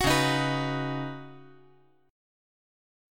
Listen to Db7sus2sus4 strummed